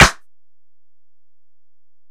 Snare (61).wav